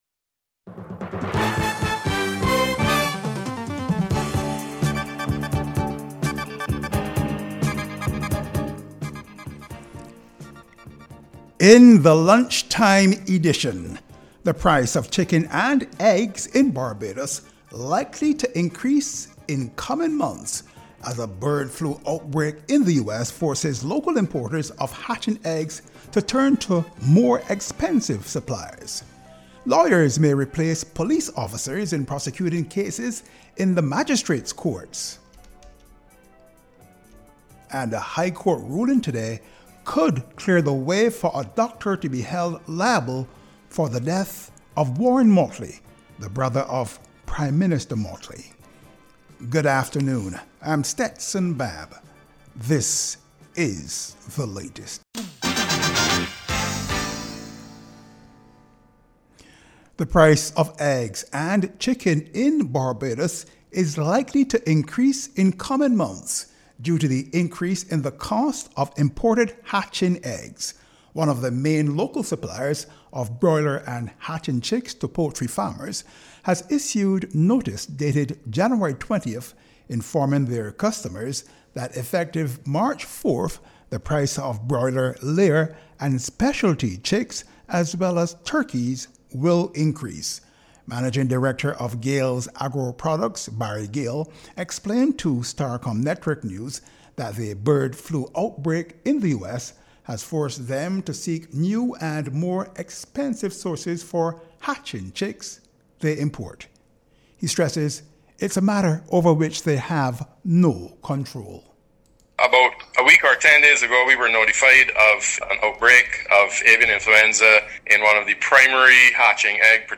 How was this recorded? The announcement was made by health officials this afternoon during a news conference.